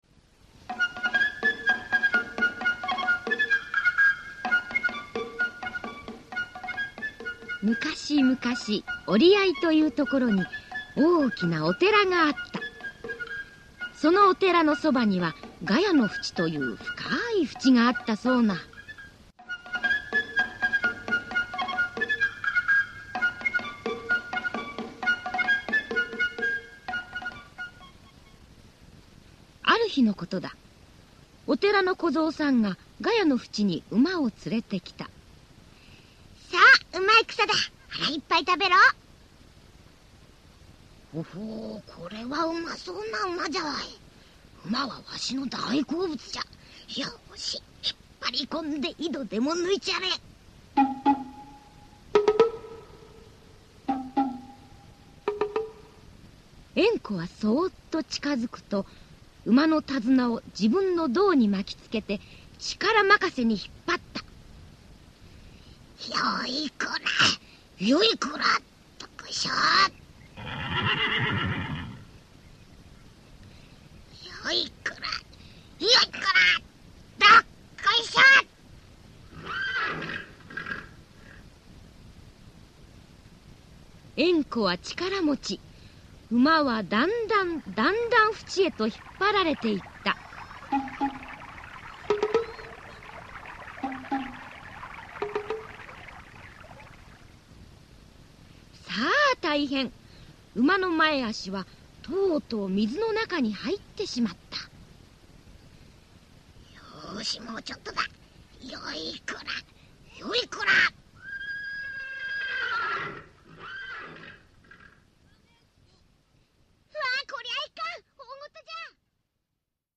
[オーディオブック] えんこのわびじょうもん